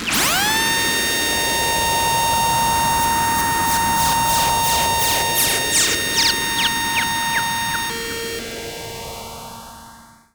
Roland E Noises
Roland E Noise 17.wav